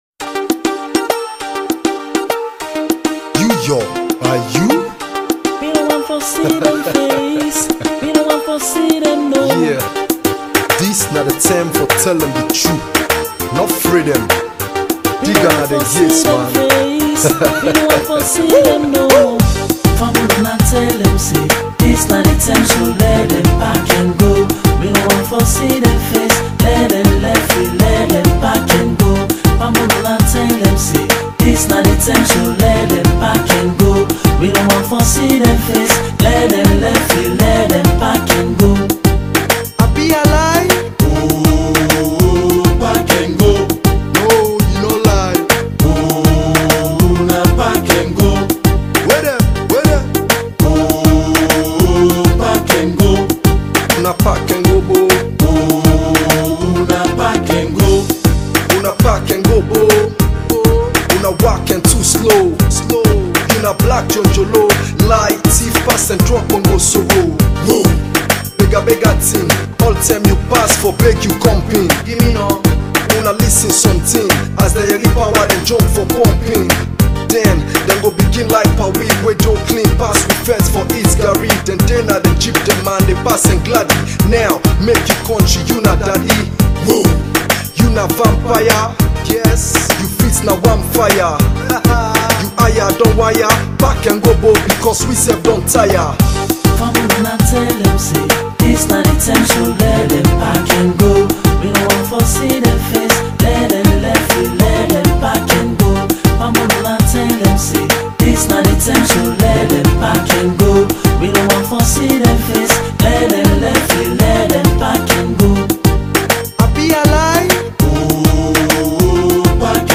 Hip-Hop
which has a revolutionary vibe.